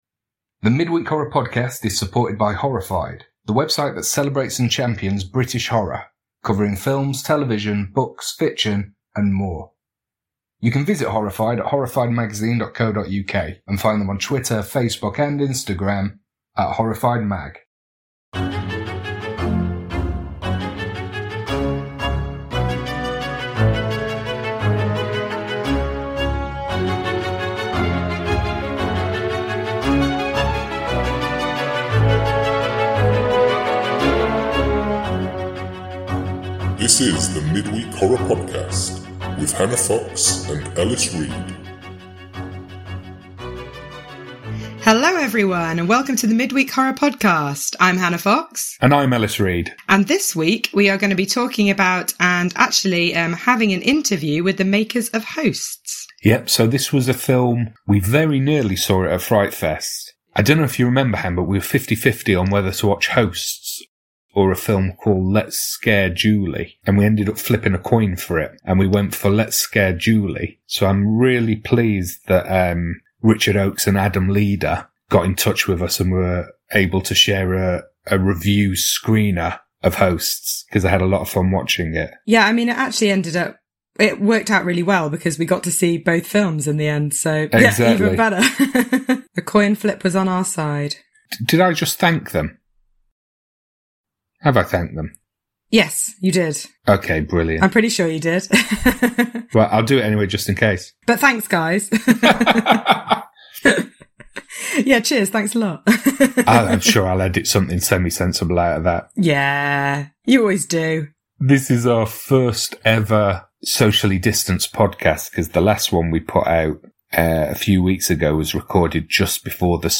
Hosts (Spoiler-Free!) and Director Q&A